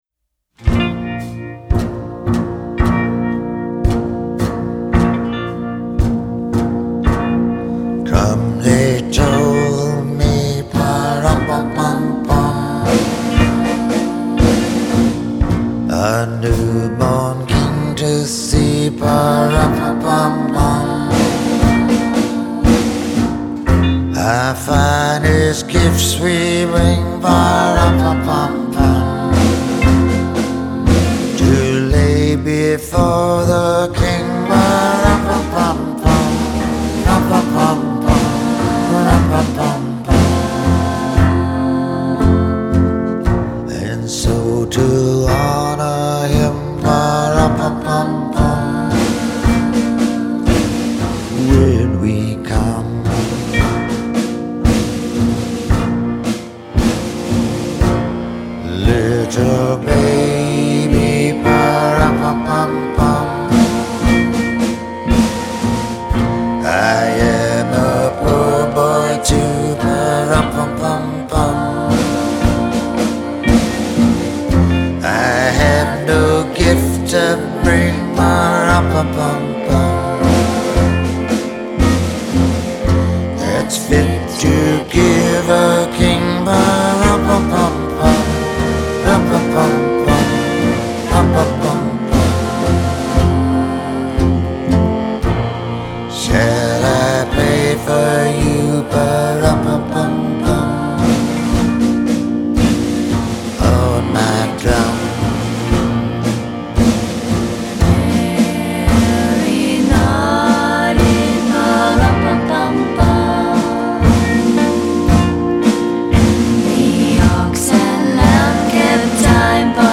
an album of Christmas standards.